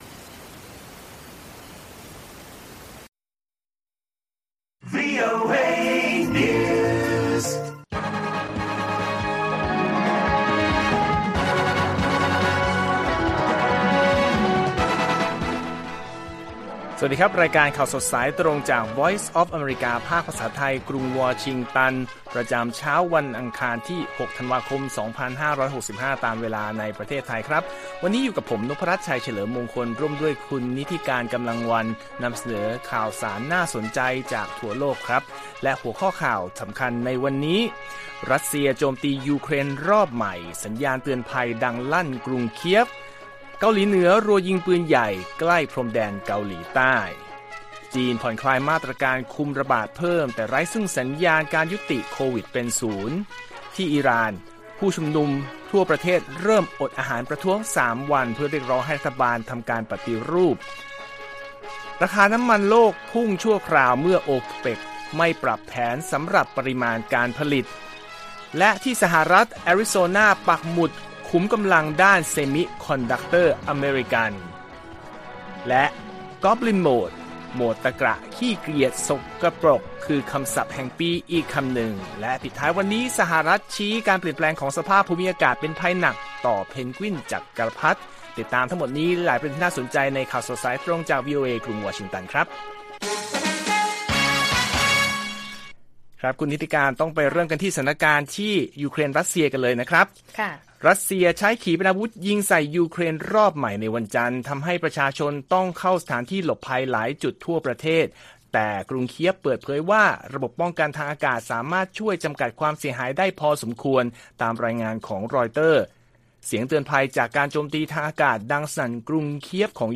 ข่าวสดสายตรงจากวีโอเอ ไทย อังคาร 6 ธันวาคม 65